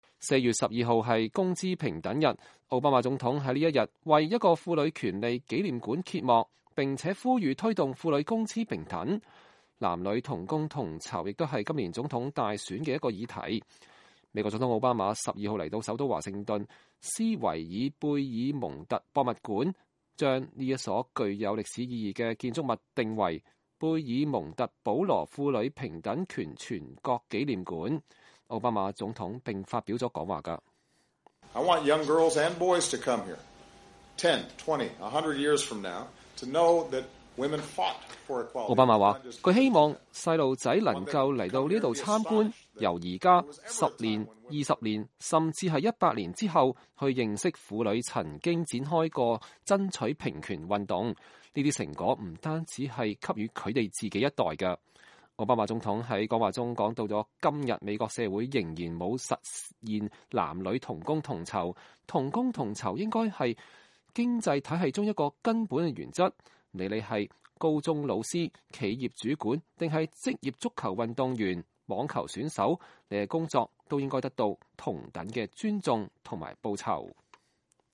奧巴馬總統發表講話